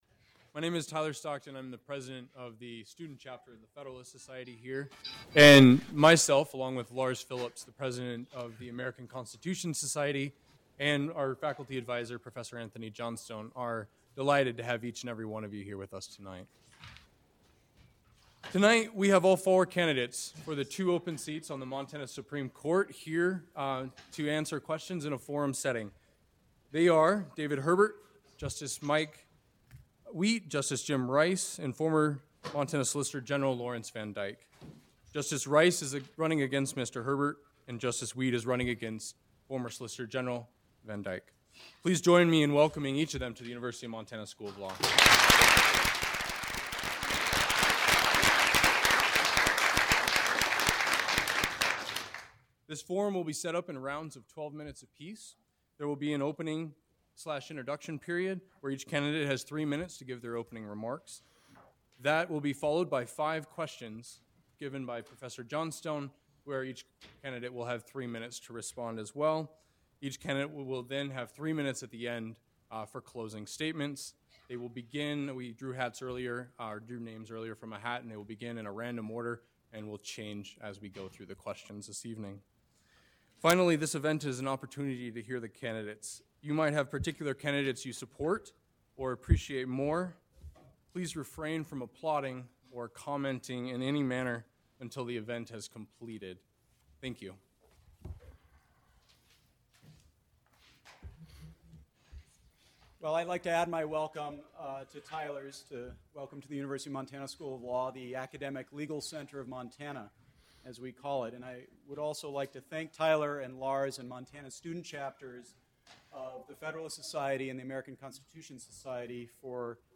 You can hear VanDyke’s partisan attacking approach at a candidate forum held in Missoula last month.